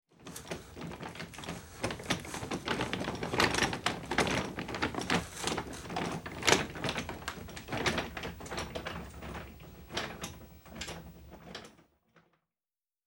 Звуки инвалидной коляски
Звук инвалидной коляски на асфальте